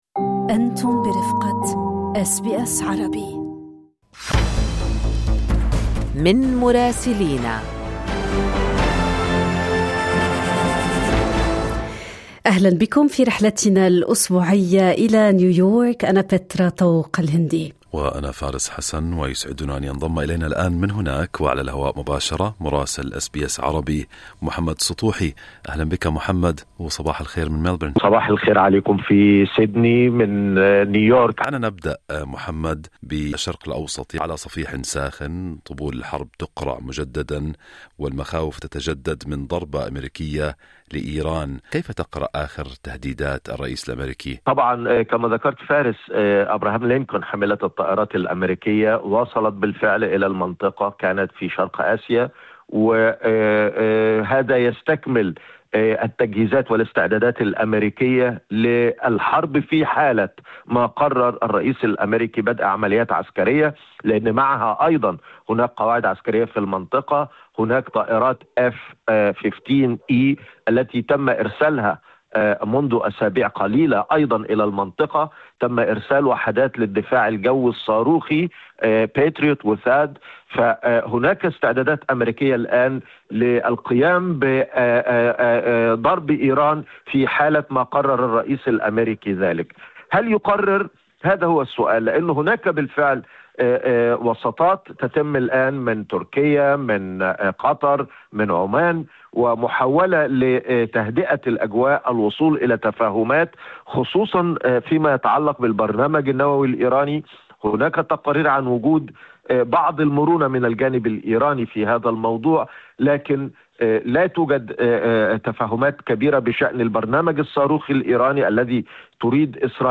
لقراءة محتوى التقرير الصّوتي، اضغط على خاصيّة Transcription في الصورة أعلاه.